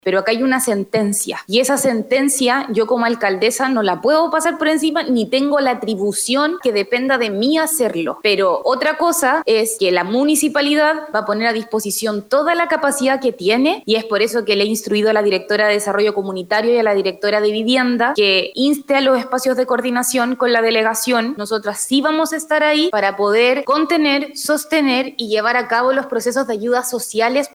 Por otra parte, en una sesión del concejo municipal, la alcaldesa de Valparaíso, Camila Nieto, indicó que como municipio no tiene las facultades para detener esta orden, sin embargo, solicitó espacios de coordinación junto a la Delegación.